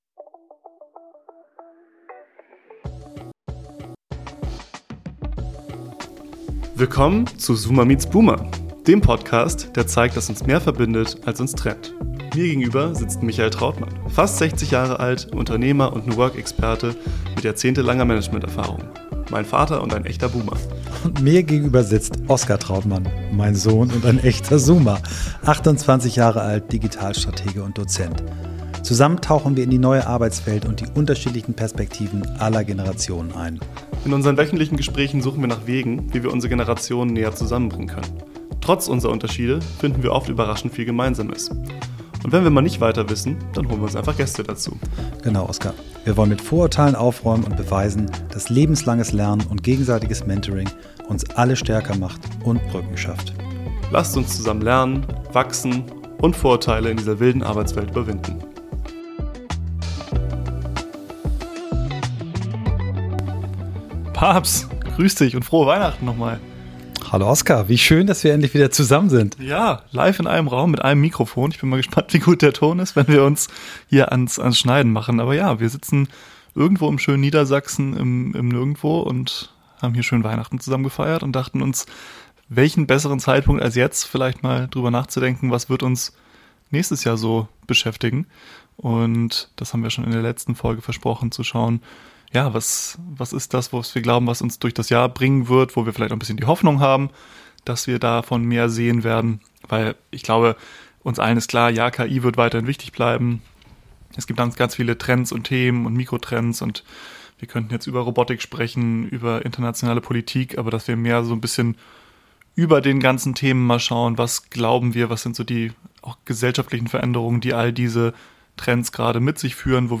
In einer ruhigen, persönlichen Folge, aufgenommen zwischen Weihnachten und Neujahr, sprechen wir über gesellschaftliche Entwicklungen, die trotz KI, Jobunsicherheit, politischer Spannungen und Umbrüche Mut machen.